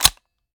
weap_uzulu_prefire_plr_01.ogg